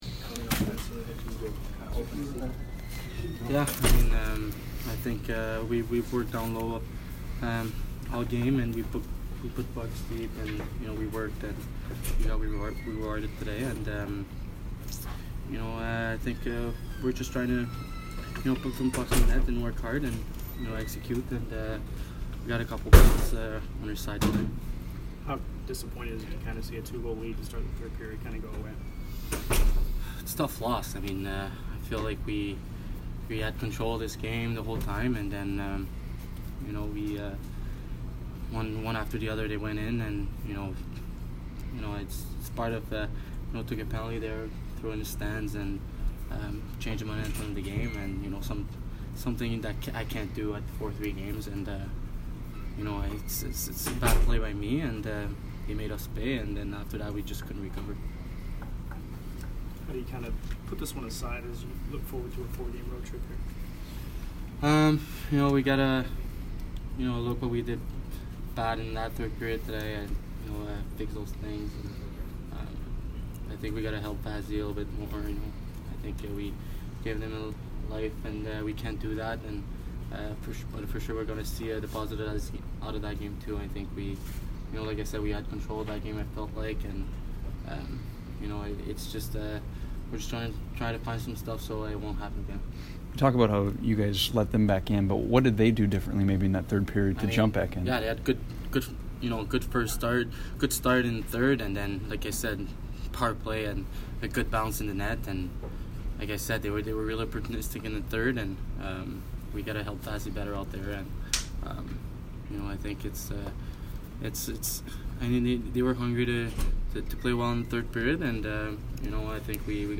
Mathieu Joseph post-game 11/10